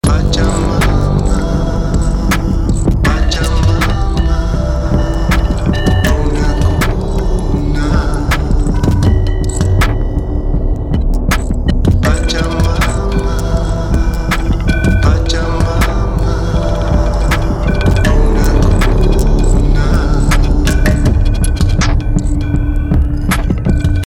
Anstatt dessen gelingt der sanfte klangliche Effekt im Hintergrund, den die weiblichen Stimmen mitbringen.